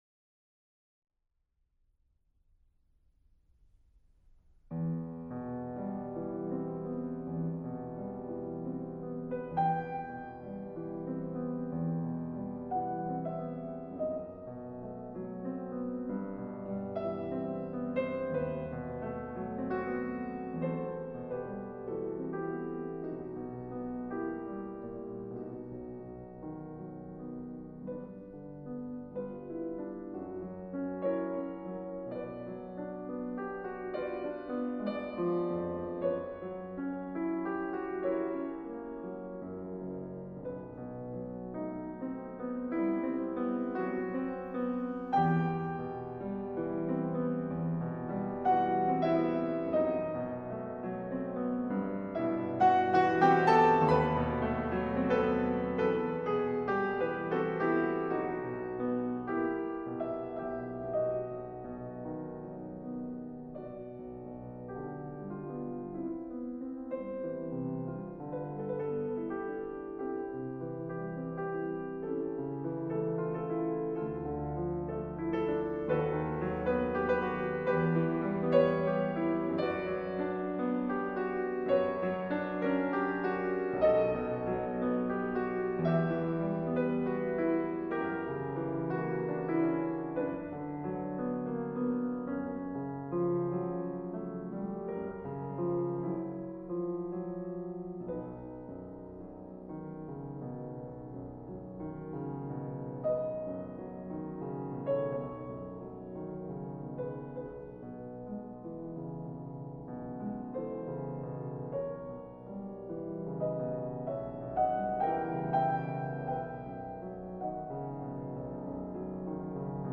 钢琴演奏